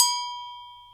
TSW AGOGOL.wav